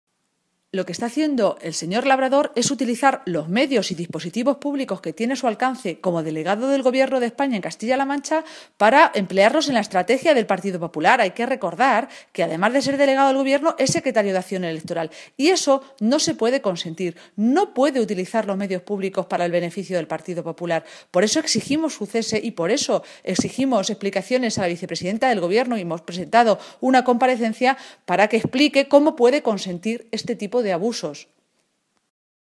Así lo ha anunciado la portavoz del PSOE en la región, Cristina Maestre, en un corte de audio remitido a los medios de comunicación.
Cortes de audio de la rueda de prensa